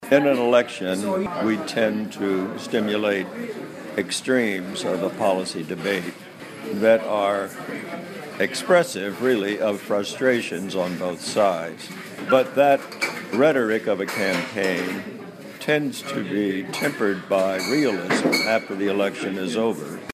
VOA专访里根国安顾问麦克法兰(2)